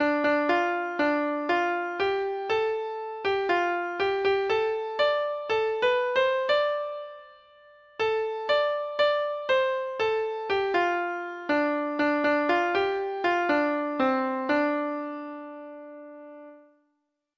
Treble Clef Instrument version